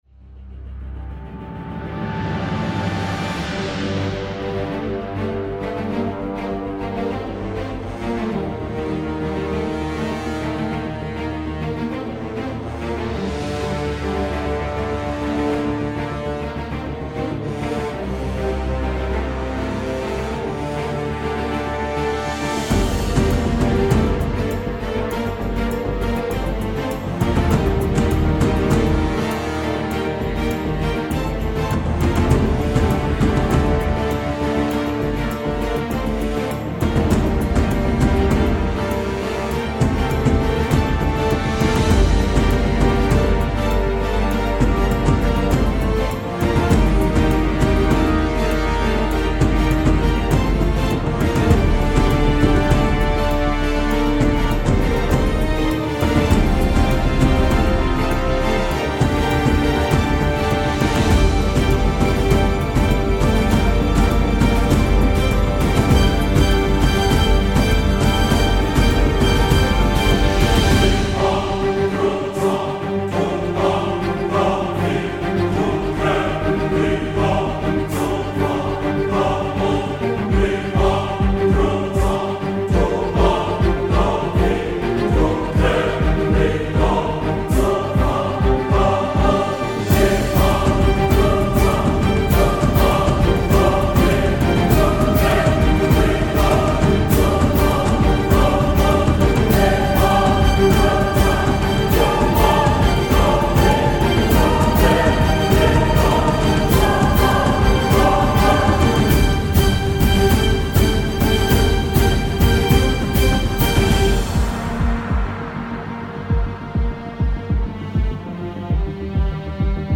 Hope you guys find this more ubscure music entertaining in some way I like it its fun and sets your mind into overdrive with imagination and is fun to listen to while doing work and medial tasks